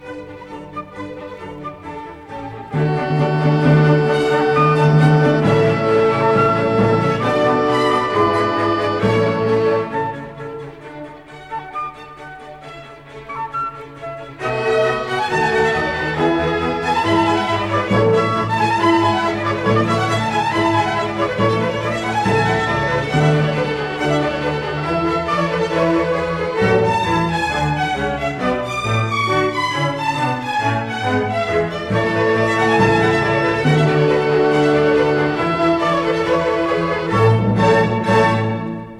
Allegro molto